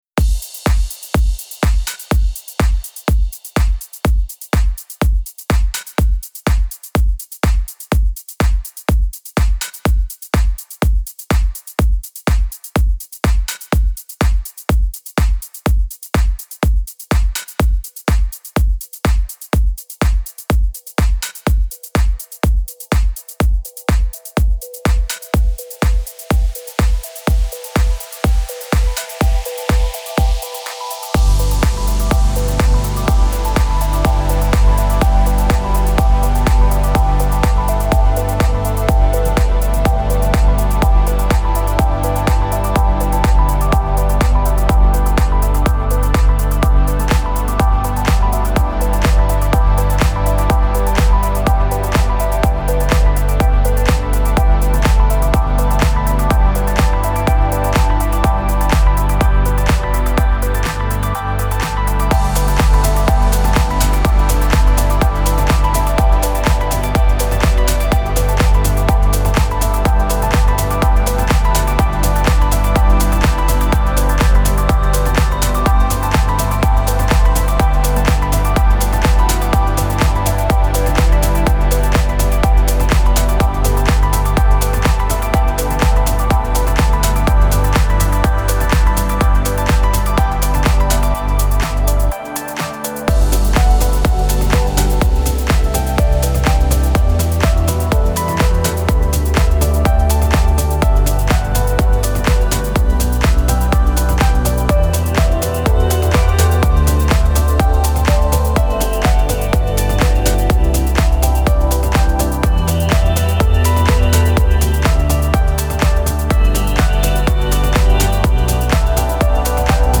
هاوس
موسیقی بی کلام پر انرژی موسیقی بی کلام ورزشی